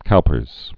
(koupərz, k-)